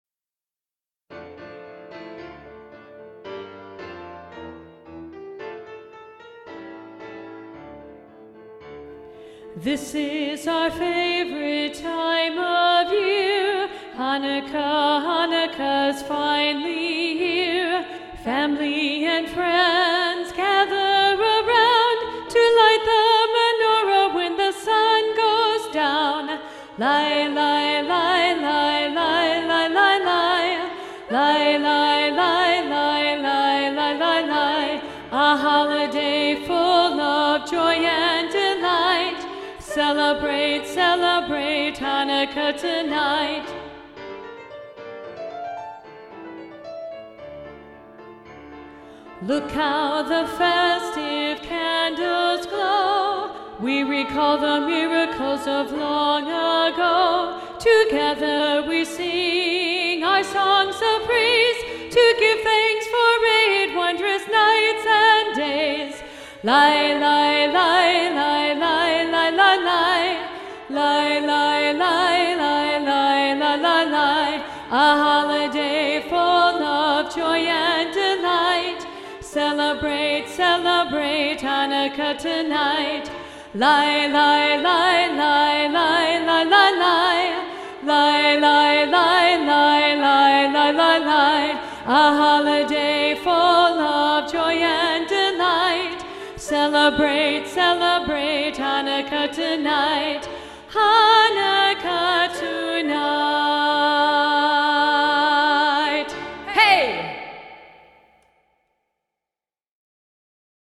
2-Part – Part 2 Predominant